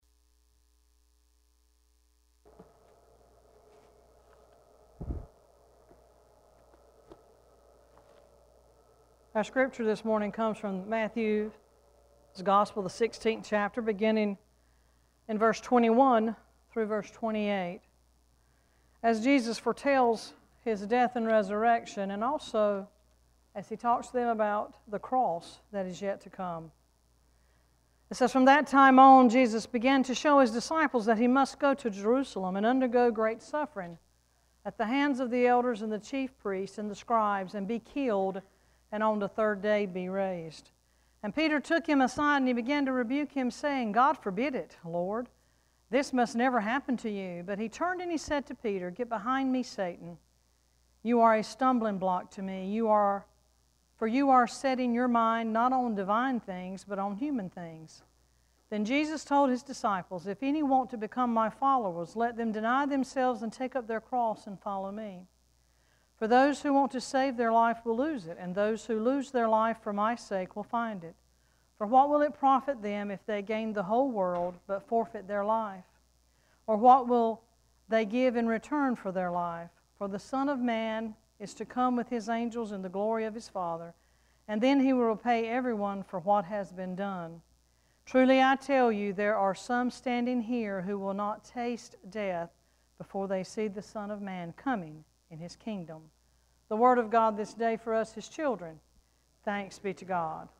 Worship Service 9-3-17: “Called to Follow”